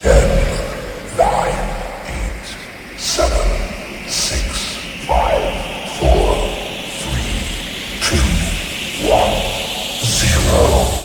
timer.ogg